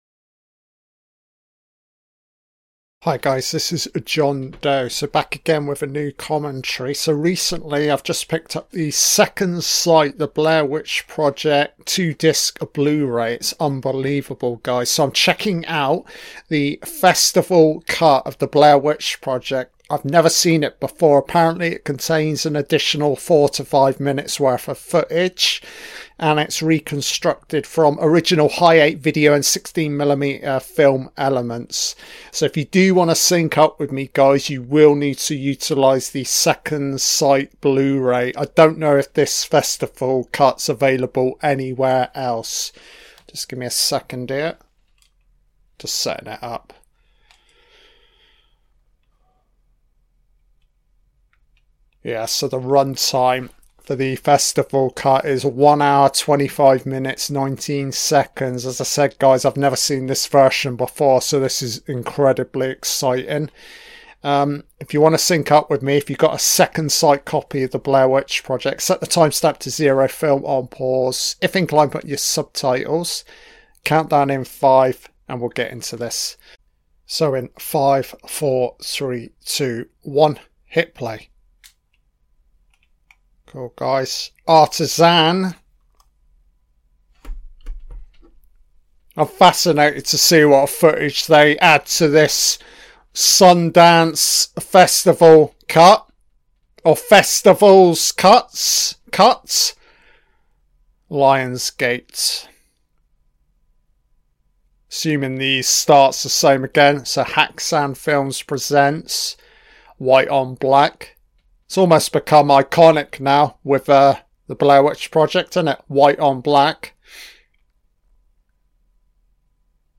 An Audio-Only Commentary on the 1999 film BLAIR WITCH PROJECT